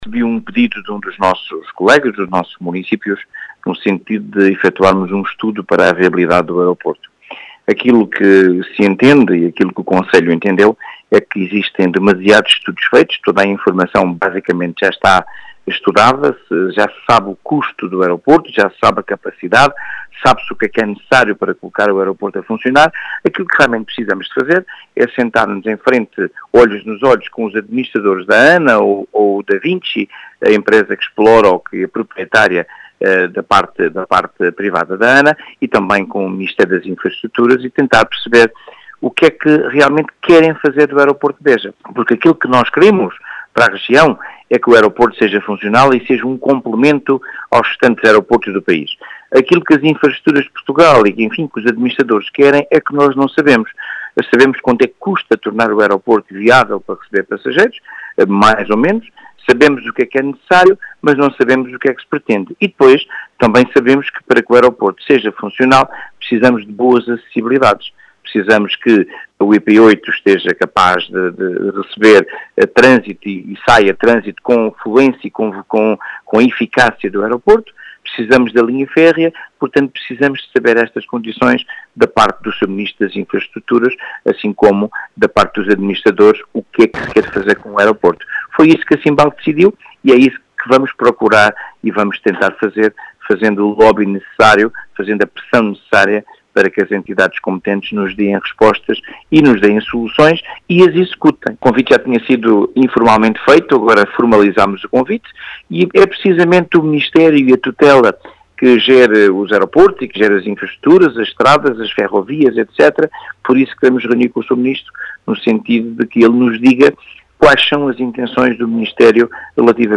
As explicações foram deixadas por António Bota, presidente da CIMBAL, que diz existirem “demasiados estudos feitos” sobre o Aeroporto de Beja, importando saber por parte da ANA, Da Vinci, e do próprio Governo,  “ o que é que realmente querem fazer do Aeroporto de Beja”.